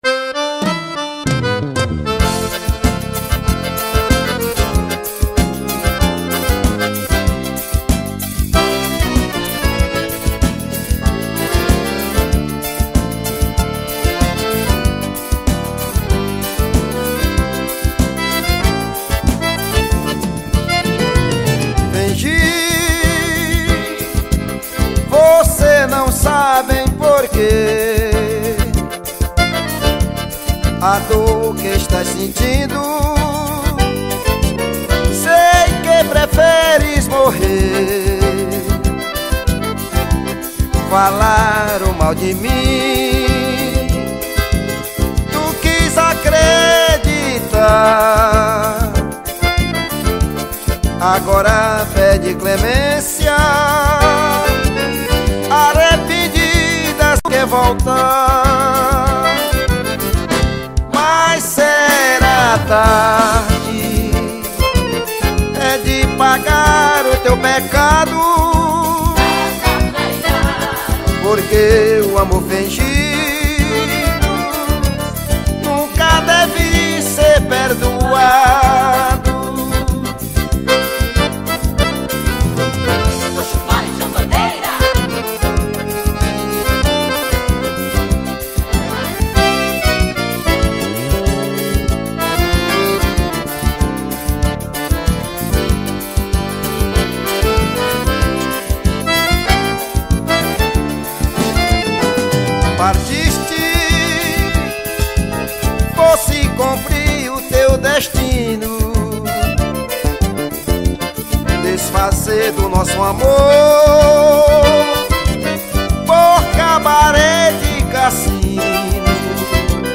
A PAUTA MUSICAL TEM MAIS UMA ROMÂNTICA DE ONTEM
forró